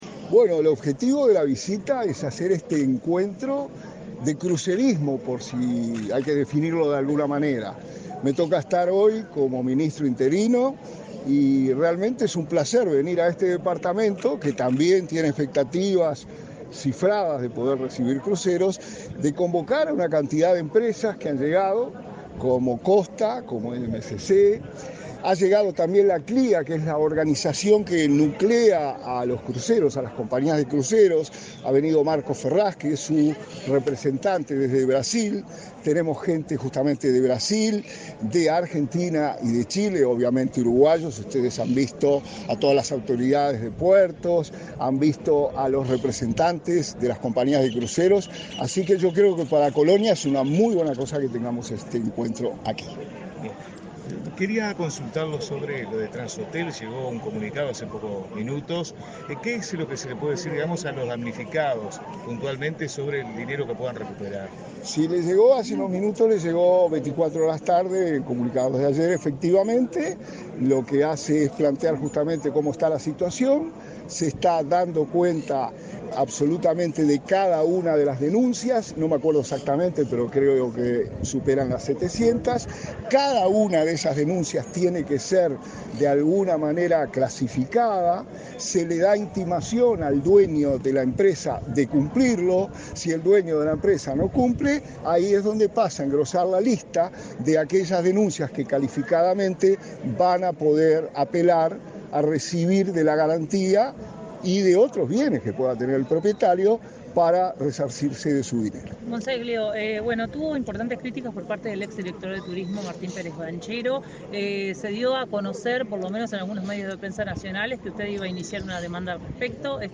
Declaraciones a la prensa del ministro interino de Turismo, Remo Monzeglio
Declaraciones a la prensa del ministro interino de Turismo, Remo Monzeglio 13/10/2022 Compartir Facebook X Copiar enlace WhatsApp LinkedIn Tras participar en el lanzamiento del VIII Encuentro Regional de Cruceros y Turismo Náutico Fluvial, este 13 de octubre, en Colonia del Sacramento, el ministro interino de Turismo, Remo Monzeglio, realizó declaraciones a la prensa.